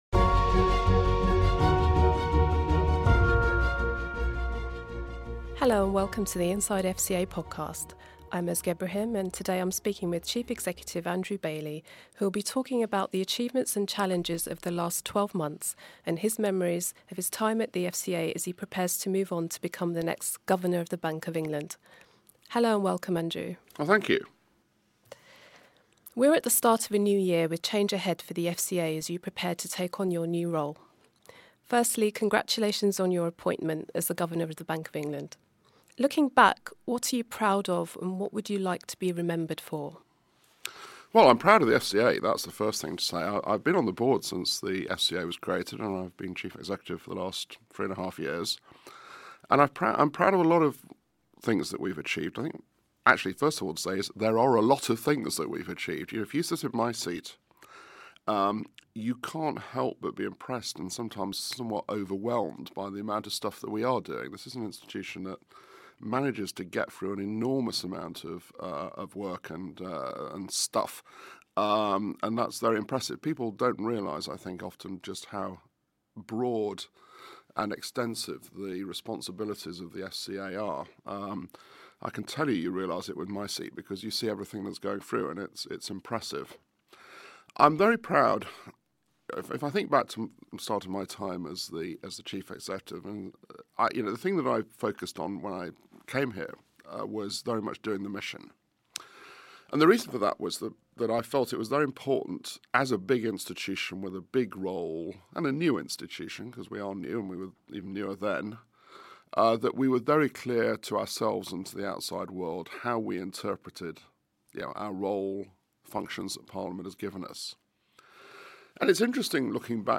Inside FCA Podcast: Andrew Bailey interview on challenges and achievements